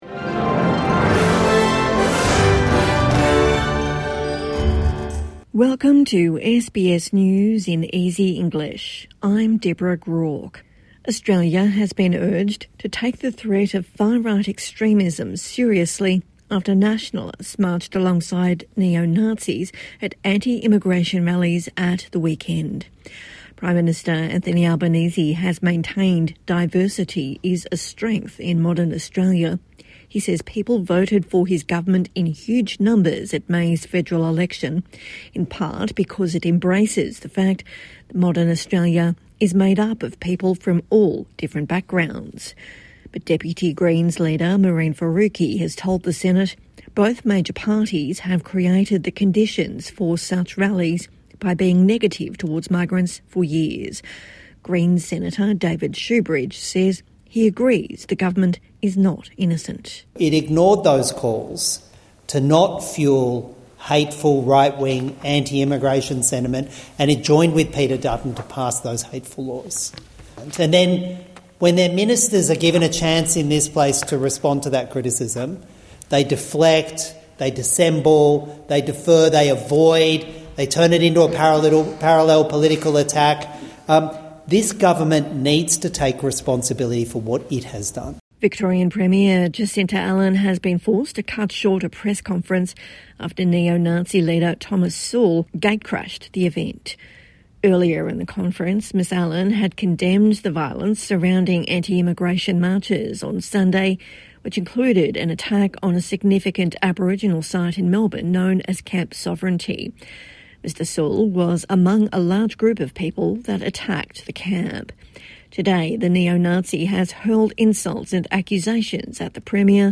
A daily 5-minute news wrap for English learners and people with disability.